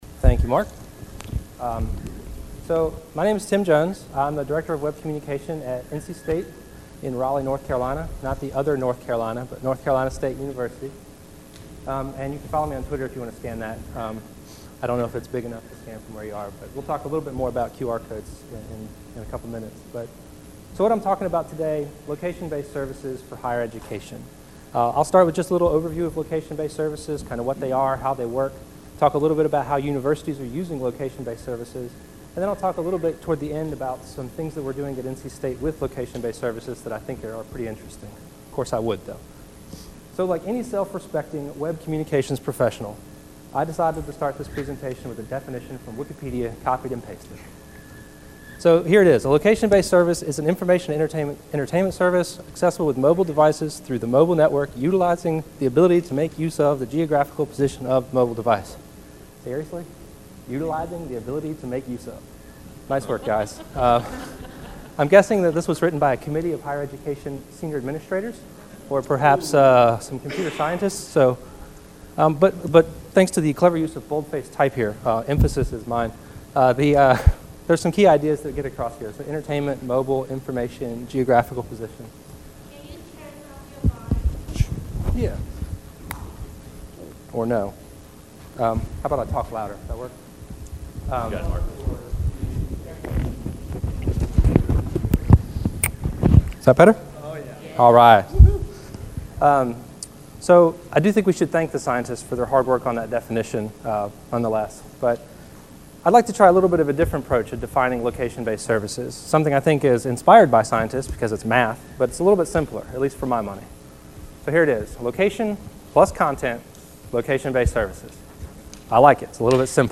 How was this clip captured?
Continental Ballroom, Mezzanine Level